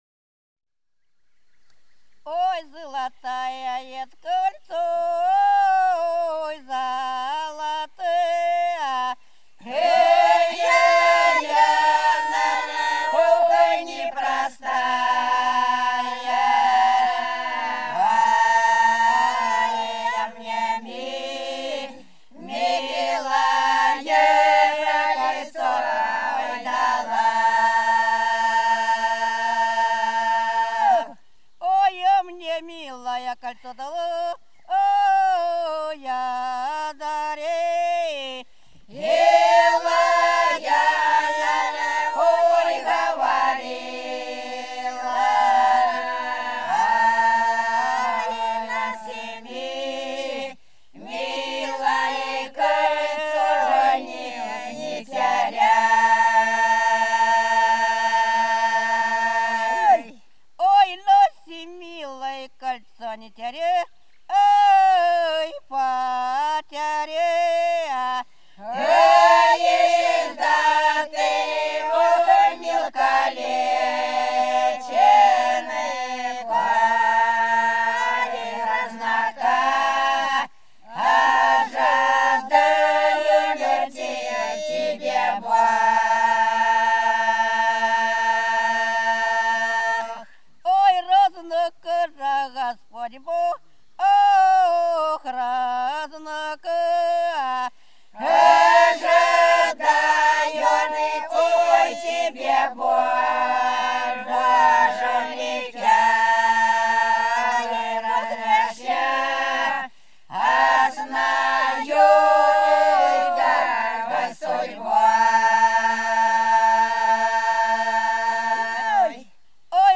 За речкою диво Кольцо - протяжная (с. Пузево)
03_Кольцо_-_протяжная.mp3